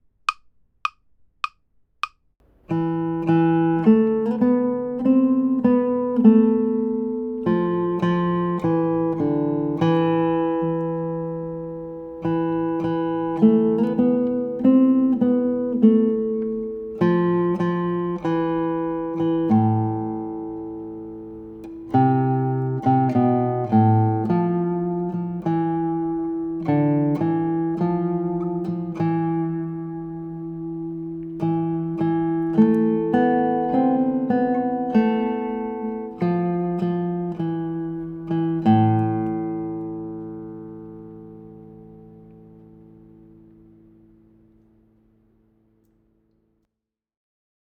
Aim for an andante tempo, about 100 BPM.
Kojo no Tsuki | Melody